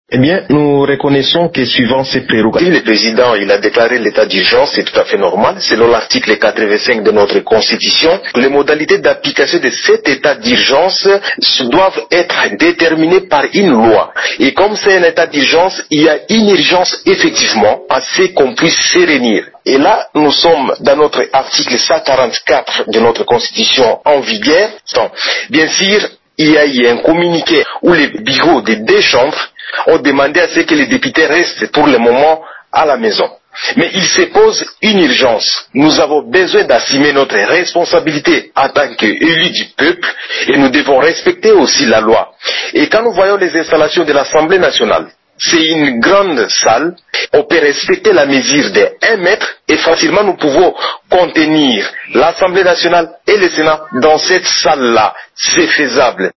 Dans une déclaration à la presse mercredi 25 mars 2020, l’élu de Bunia estime qu’il est possible de réunir les parlementaires dans la salle de congrès en respectant la distance d’un mètre et sans les exposer à la contamination au Coronavirus.
Vous pouvez écouter la déclaration du député Gracien Iracan